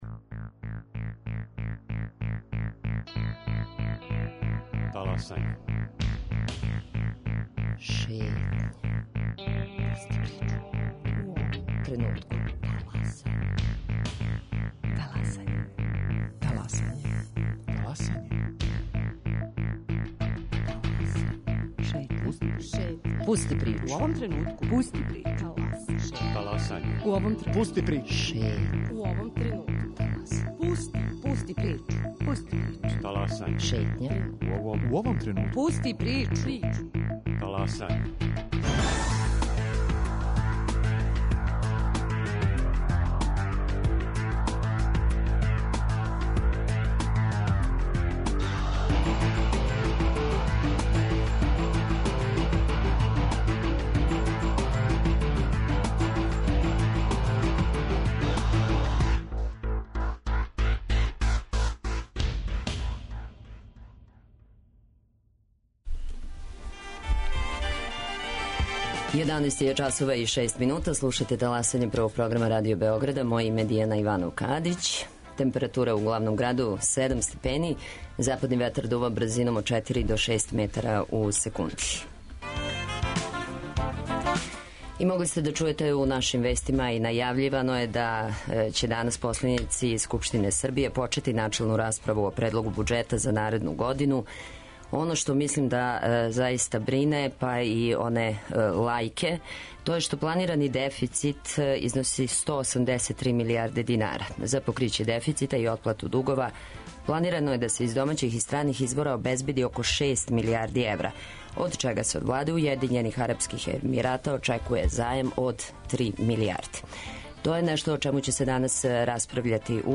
Гости: Верољуб Арсић, посланик Српске напредне странке, Божидар Ђелић, посланик Демократске странке